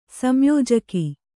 ♪ samyōjaki